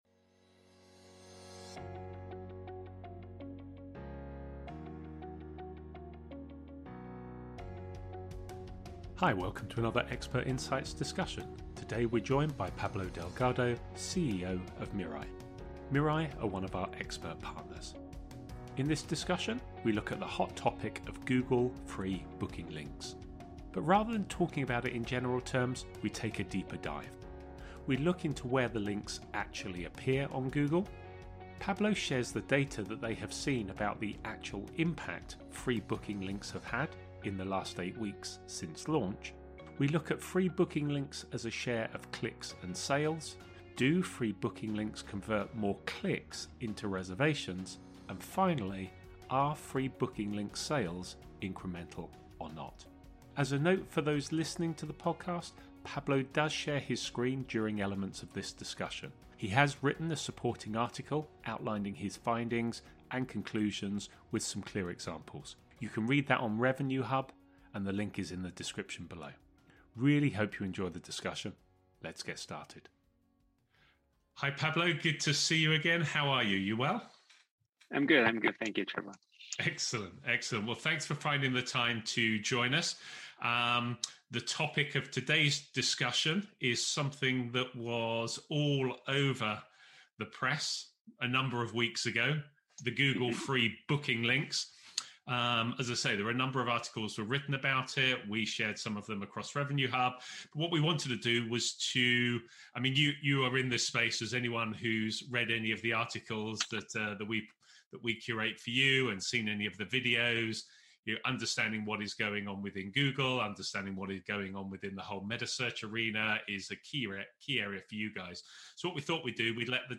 Welcome to another Expert Insights discussion.